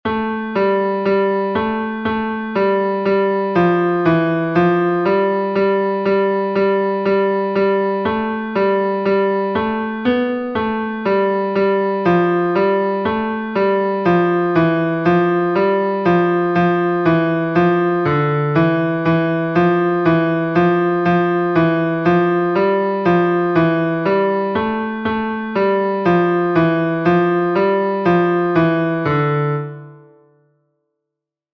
"Vidi angelum dei," the first responsory verse from the second nocturn of Matins, Common of Apostles